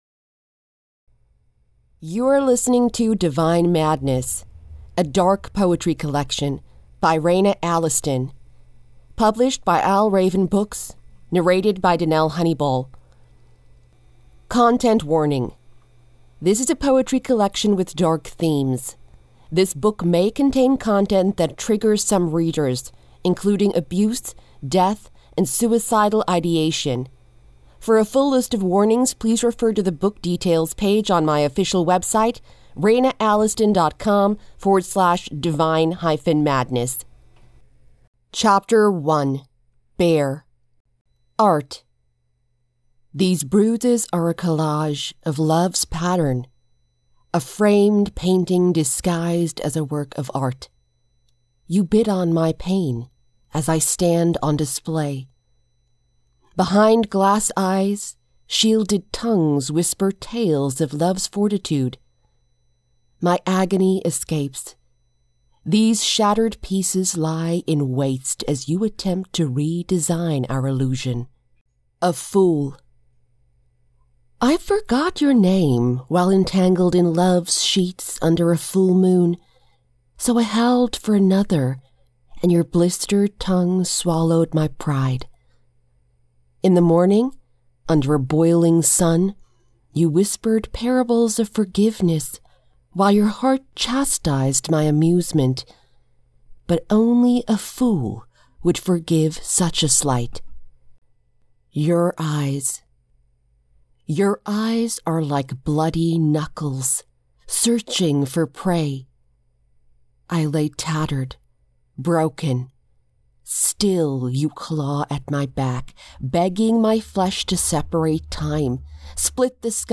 Genre: Dark Poetry
You can purchase the Divine Madness audiobook directly from my Official Shop.